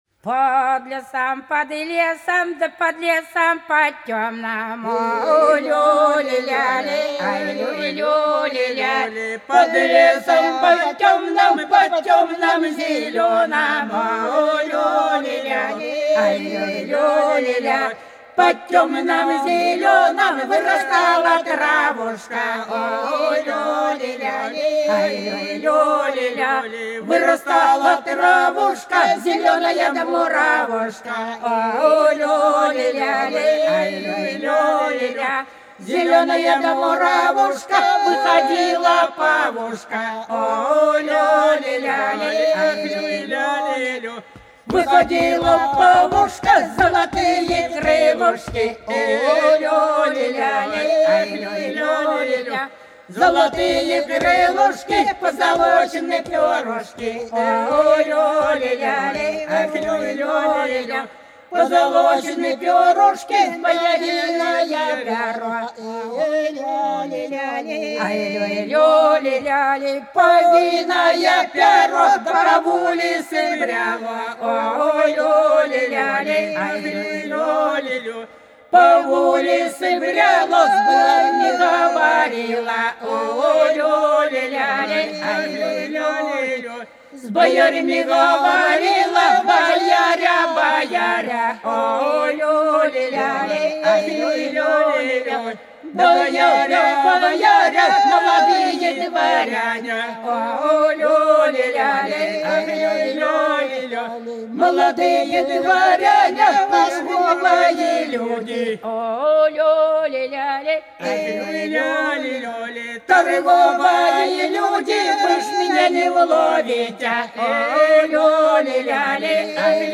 По-над садом, садом дорожка лежала Под лесом, под лесом, да под лесом, под тёмным - плясовая (с.Плёхово, Курская область)
09_Под_лесом,_под_лесом,_да_под_лесом,_под_тёмным_(плясовая).mp3